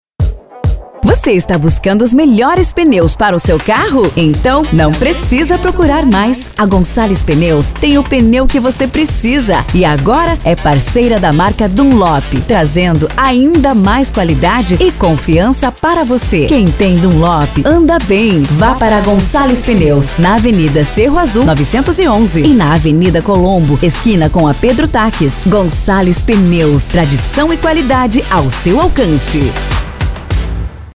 JOVEM: